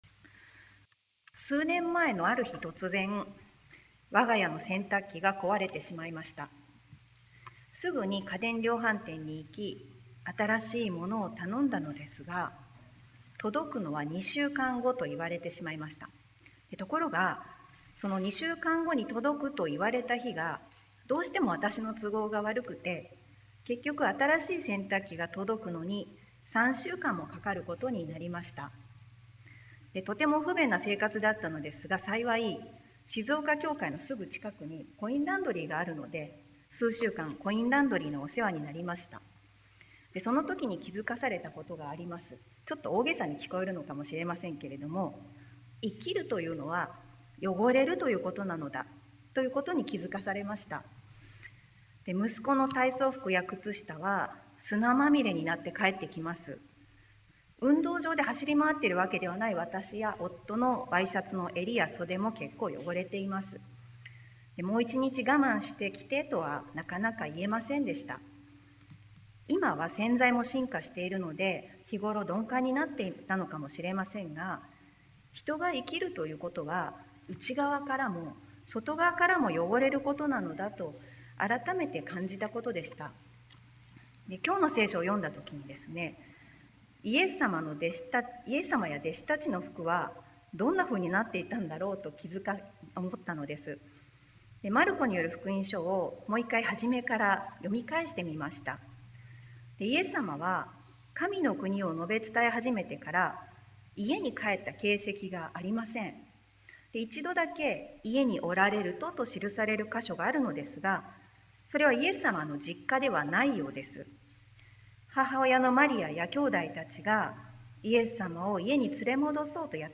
sermon-2021-10-03